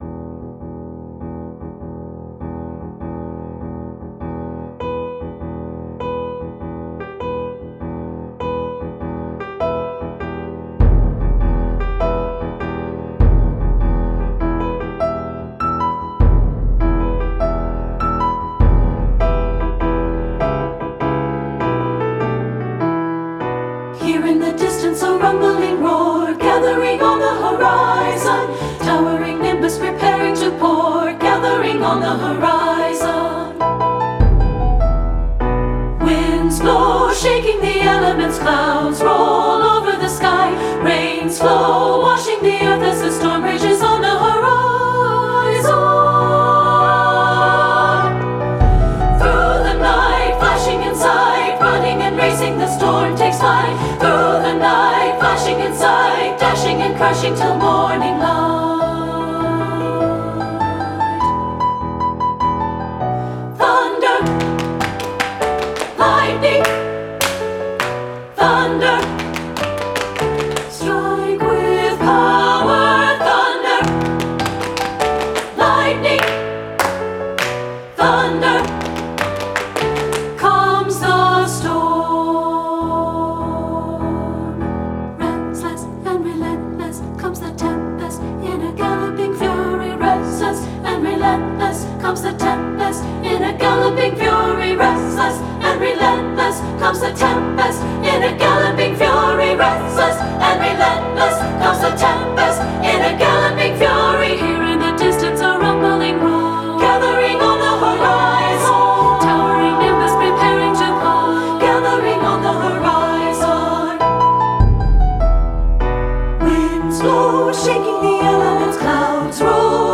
SSA Voices with Piano and Optional Percussion
• Soprano 1
• Soprano 2
• Alto
• Piano
• Drum
Studio Recording
Ensemble: Treble Chorus
Key: D major
Tempo: q. = 100
Accompanied: Accompanied Chorus